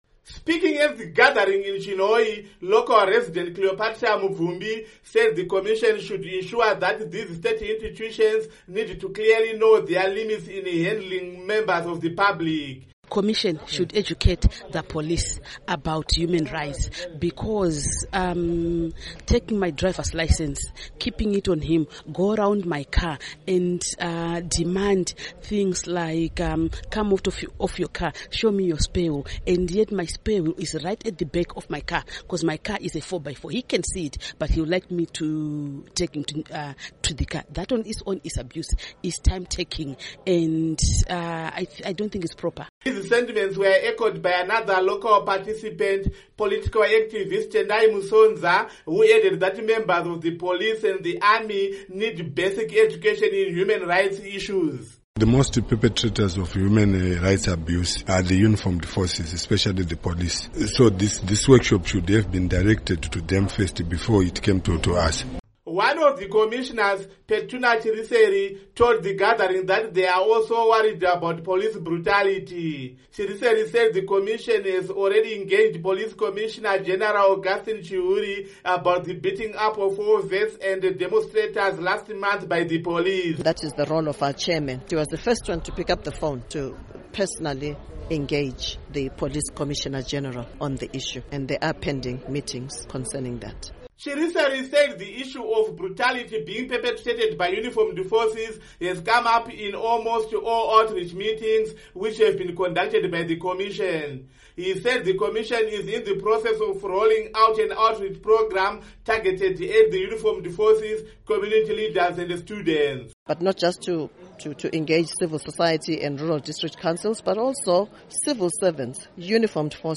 Report on Human Rights Abuses